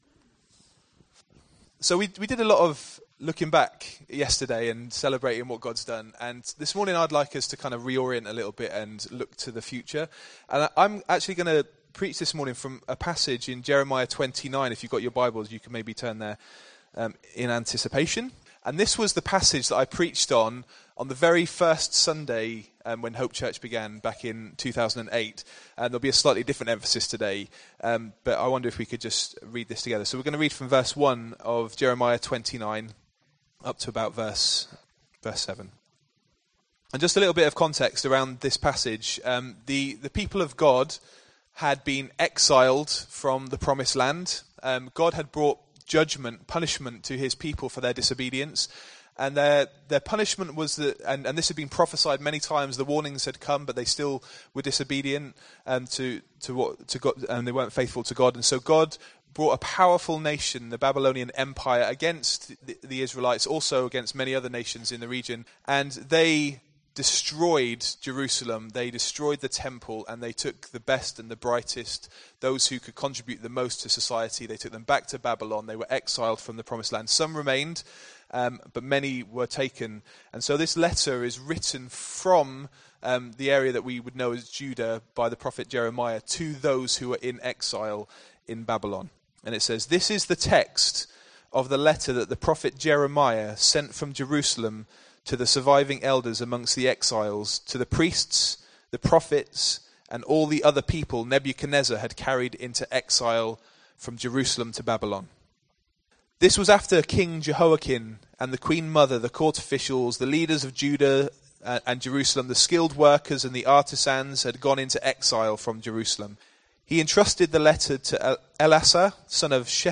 City Within a City | Hope Church, Corby